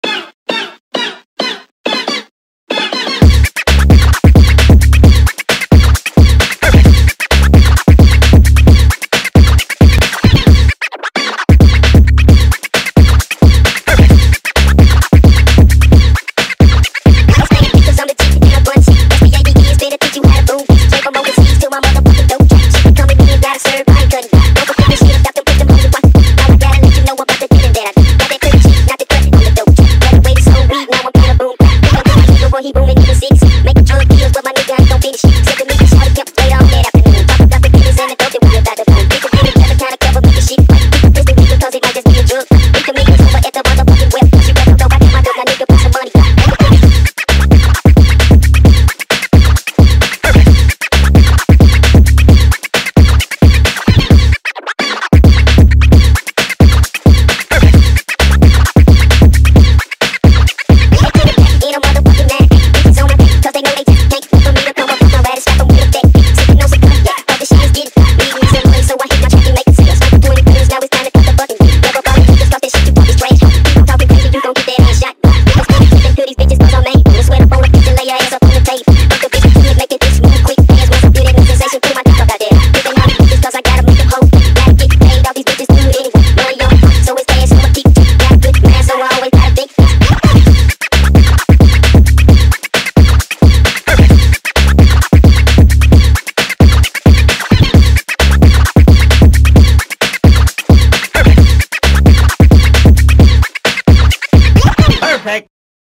با ریتمی سریع شده
فانک